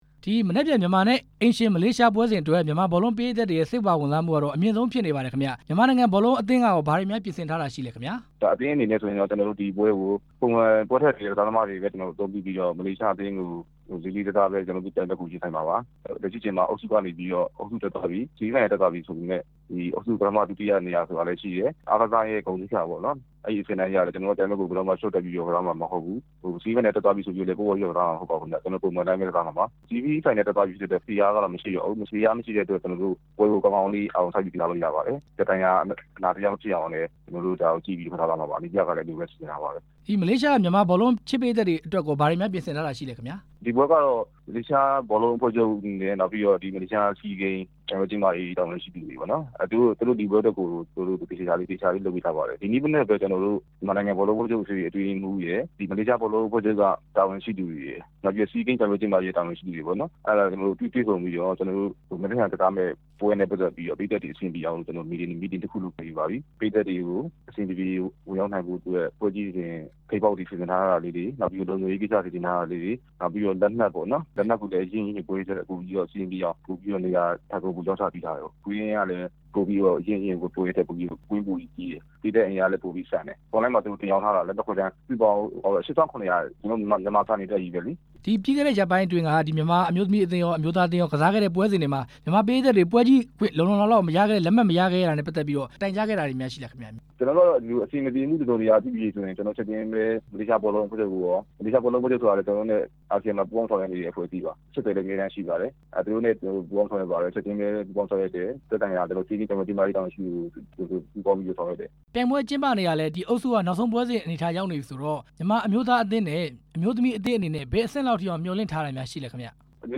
မလေးရှားနဲ့ မြန်မာ ဘောလုံးပွဲ ခွဲတမ်းလက်မှတ်ရရှိထားတဲ့ အကြောင်း မေးမြန်းချက်